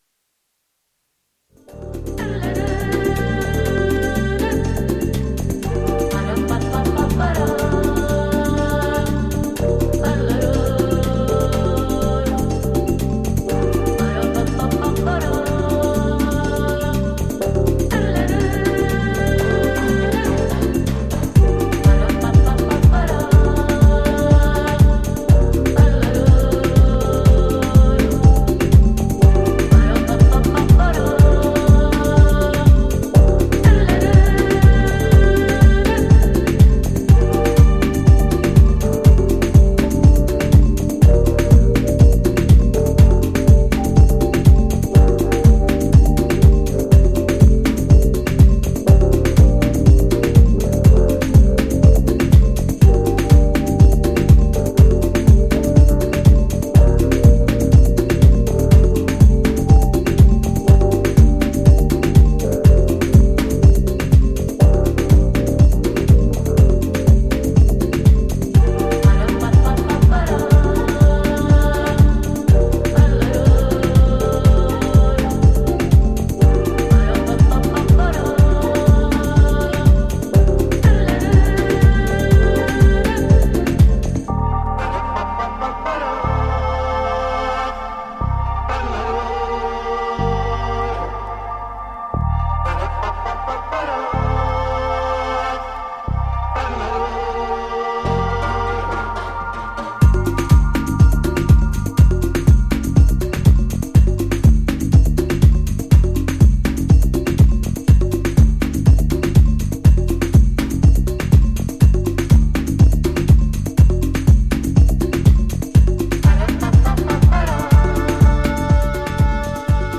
ディスコ/テック/バレアリックといったフロア受け抜群のハウスを展開するダンス・トラック全4曲を収録。
ジャンル(スタイル) HOUSE / DISCO HOUSE